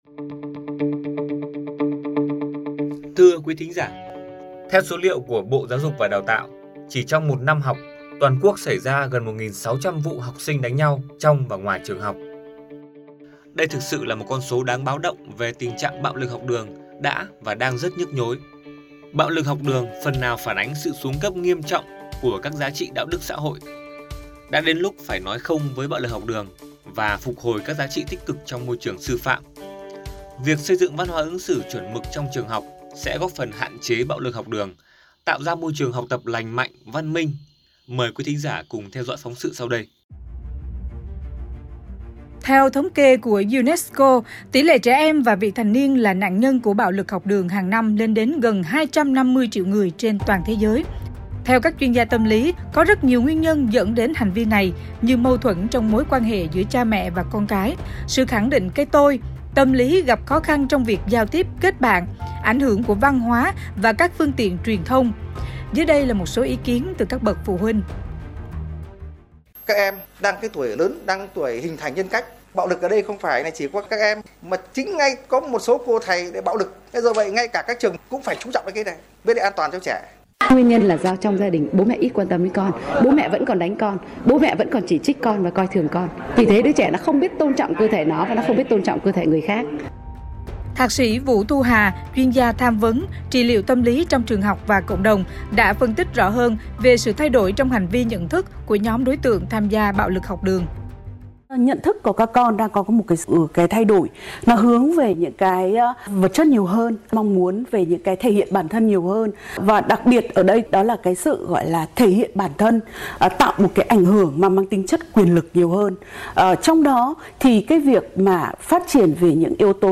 File phát thanh